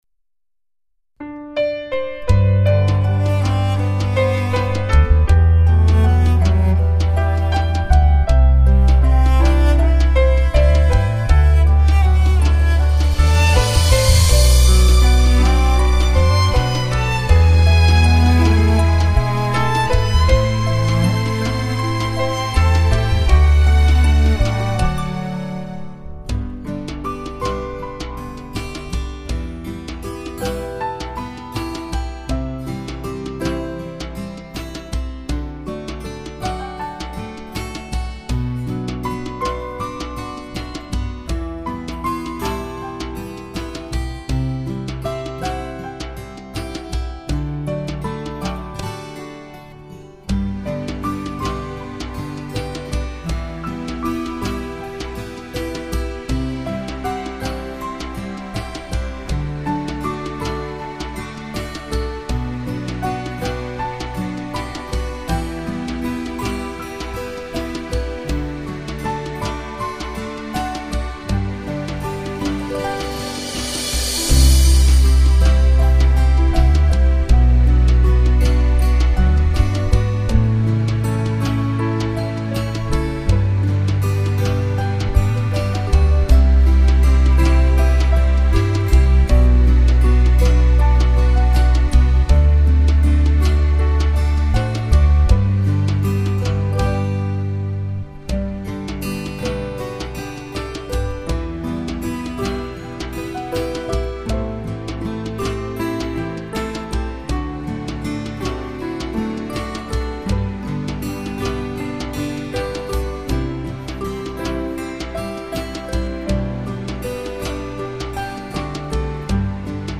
直笛音色淳樸又親切，當直笛聲響起，我們彷彿回到童年的美好時光。
目前最常見的直笛是從巴洛克笛改良而成，音域廣且音色清亮。